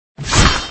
魔哀攻击.ogg